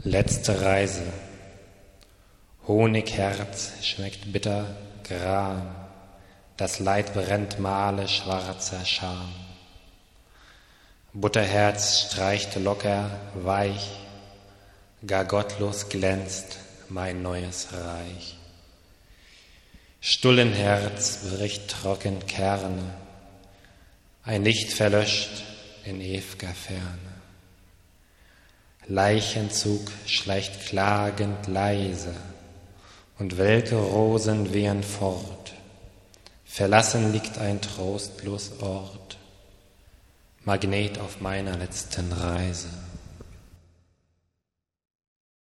Hier gibt es einige improvisierte Lesungen meiner Texte zum Herunterladen im MP3-Format, in seltenen Fällen sind sie musikalisch untermalt. Es handelt sich um Lo-Fi Produktionen, wenn man sie überhaupt Produktionen nennen mag.